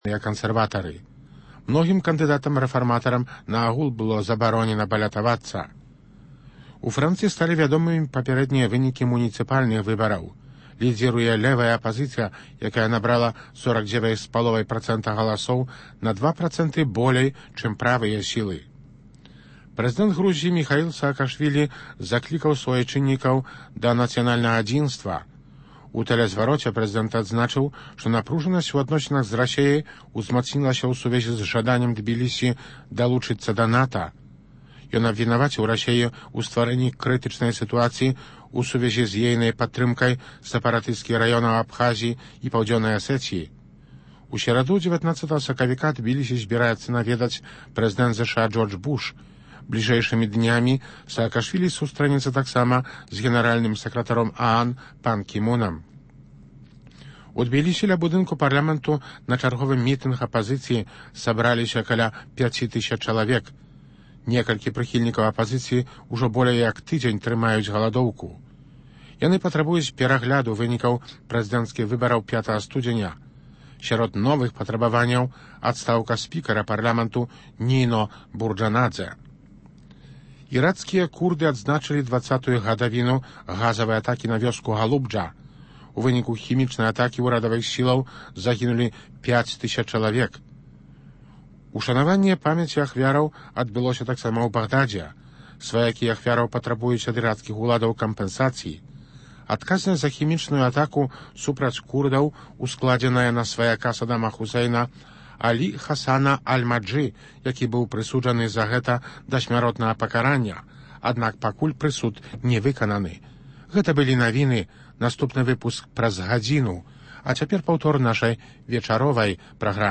Круглы стол аналітыкаў, абмеркаваньне галоўных падзеяў тыдня